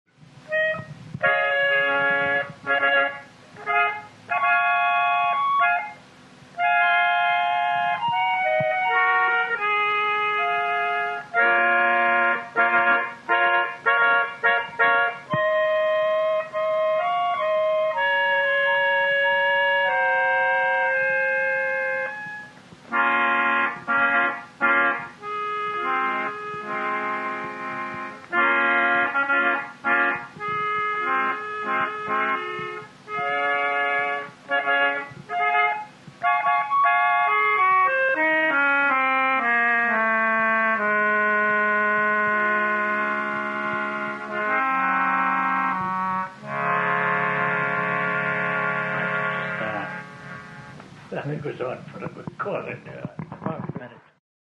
Nevertheless, he gladly shared some other fragments from his once extensive repertory:
Overture Fragment